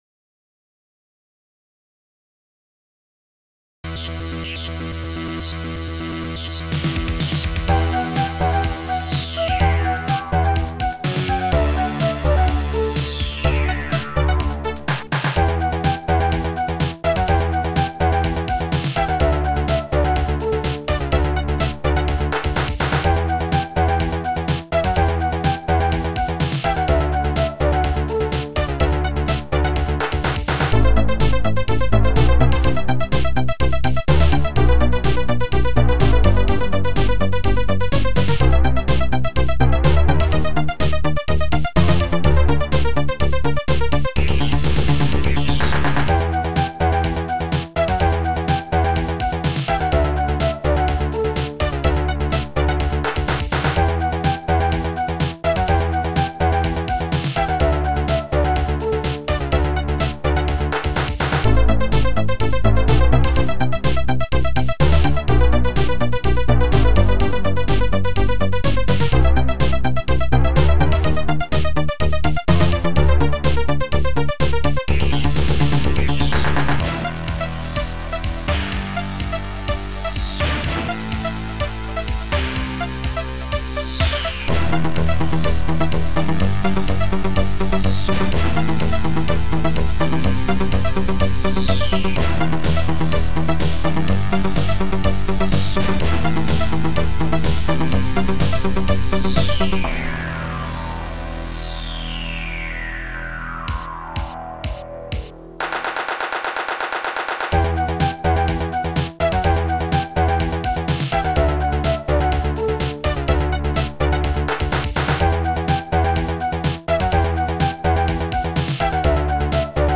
orch.hit
dxbass
CLAPS.VOC
STRING.VOC
SHAKUHA.VOC
SYNBRASS.VOC